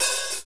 Index of /90_sSampleCDs/Northstar - Drumscapes Roland/DRM_Hip-Hop_Rap/HAT_H_H Hats x
HAT H H OH08.wav